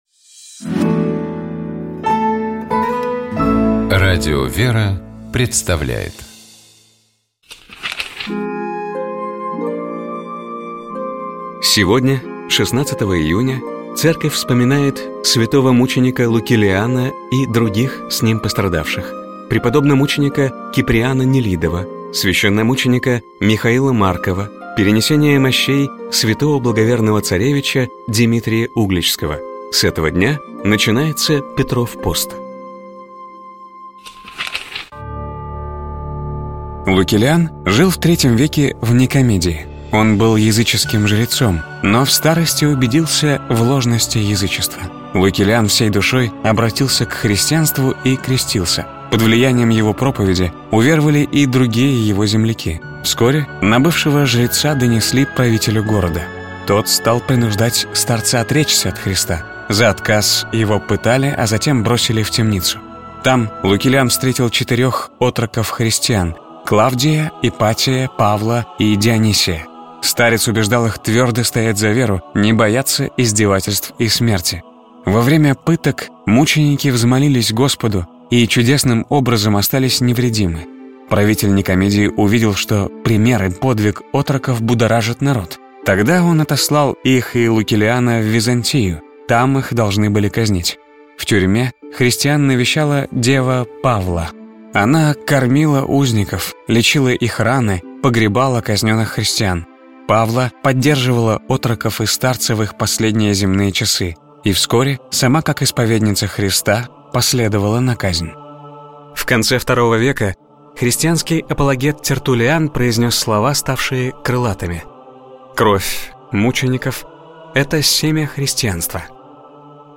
Псалом 10. Богослужебные чтения